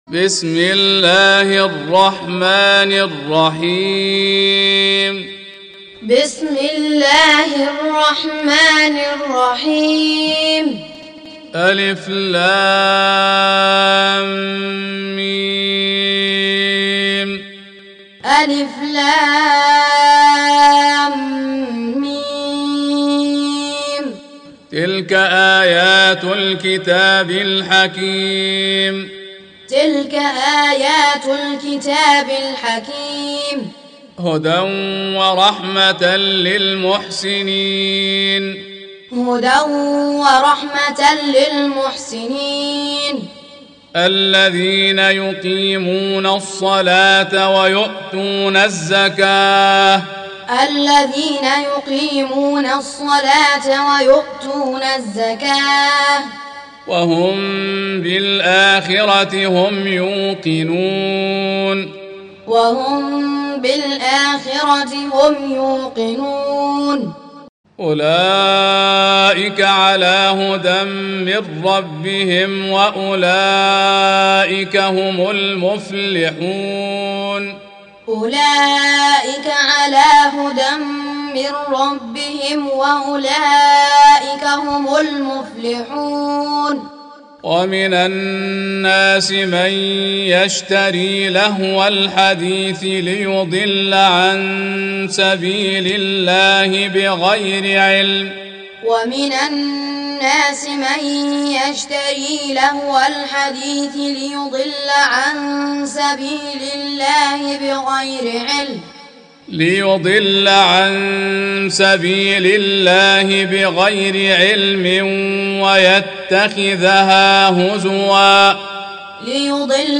31. Surah Luqm�n سورة لقمان Audio Quran Taaleem Tutorial Recitation